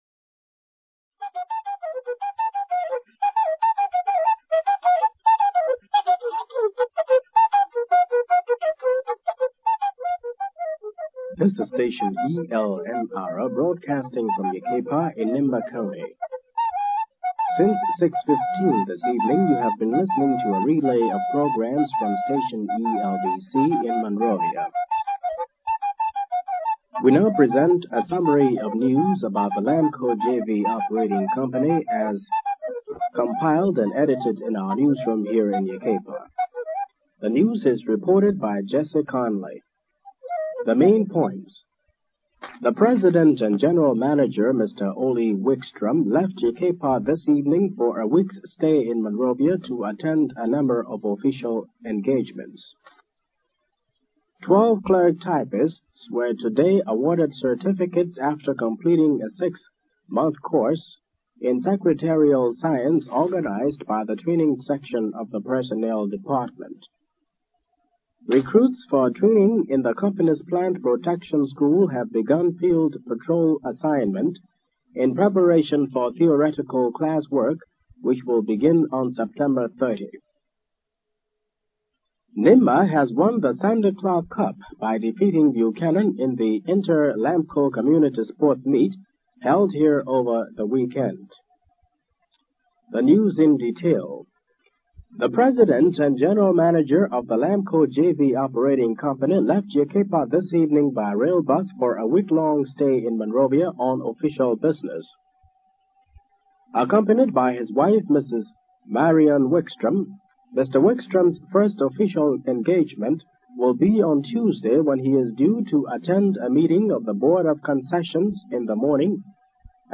Liberian radio news